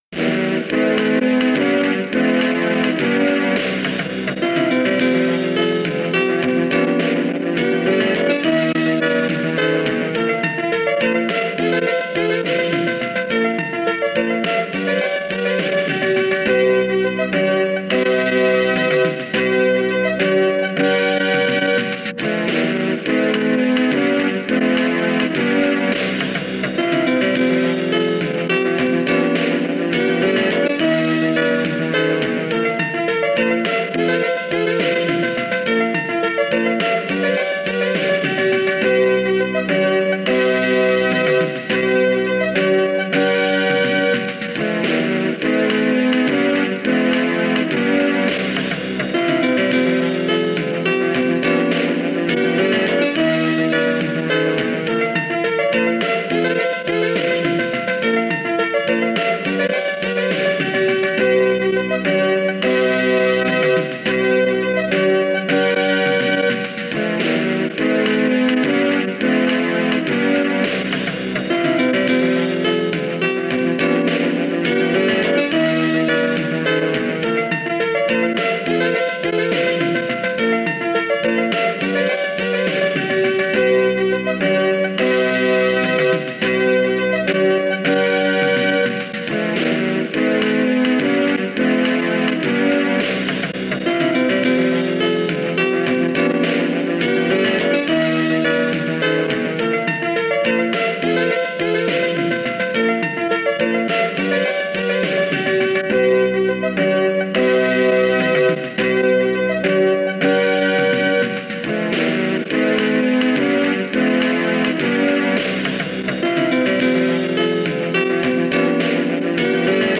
Sintonia instrumental de la cadena.